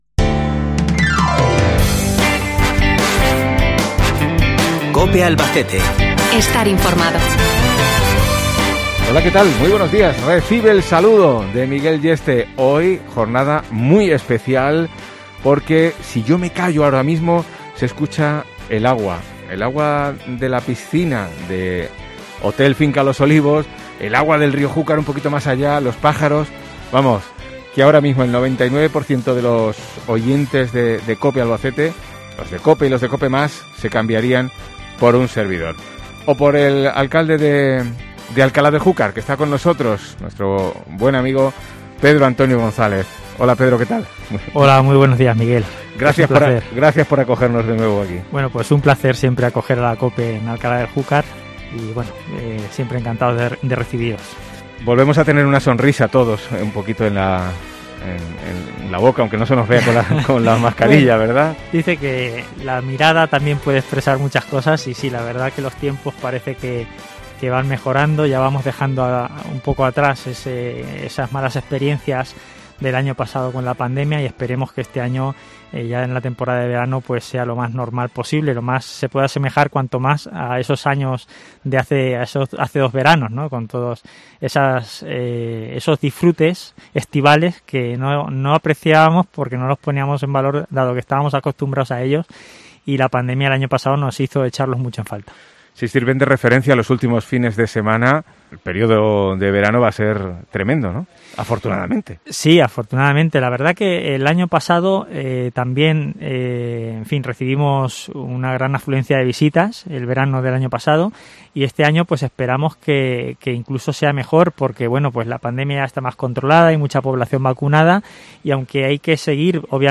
AUDIO: Abrimos el programa especial desde Alcalá del Júcar con el alcalde Pedro Antonio González